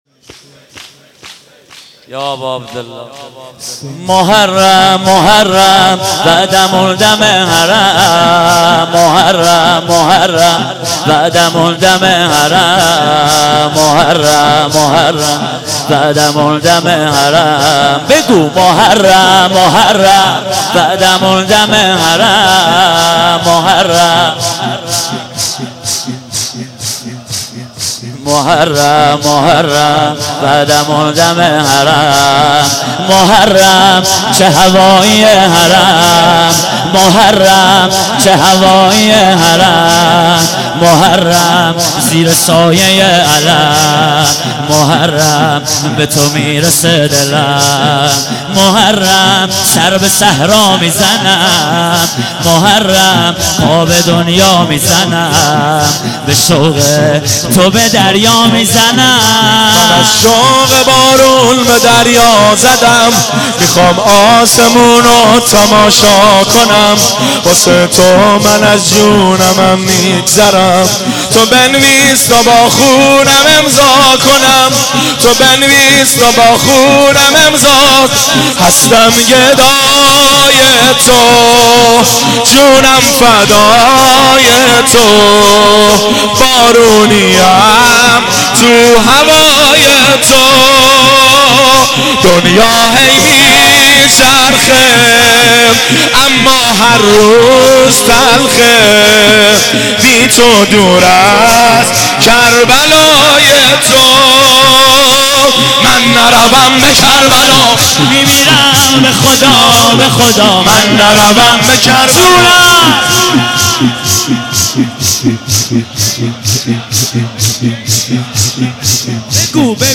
مداحی کامل شب چهارم محرم ‌94
که در شهر بابل اجرا شده، آماده دریافت است.
که در هیئت غریب مدینه امیرکلا اجرا شده، آماده دریافت است.